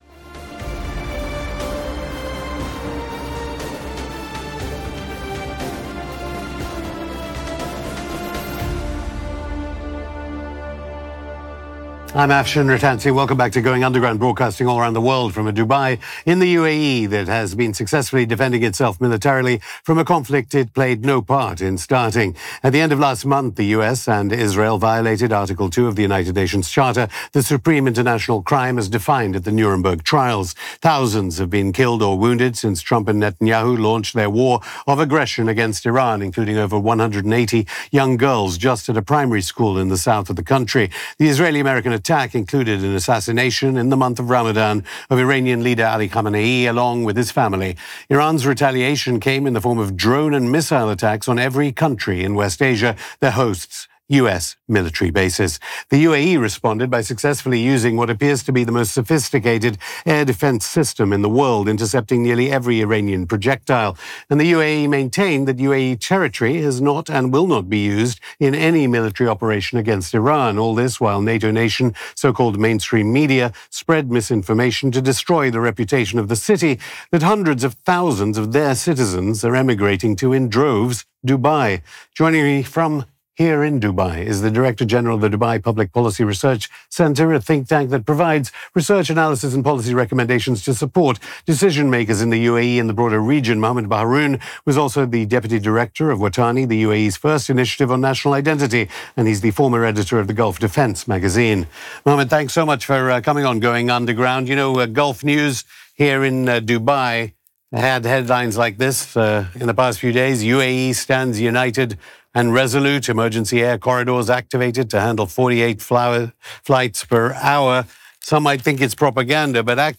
Going Underground Hosted by Afshin Rattansi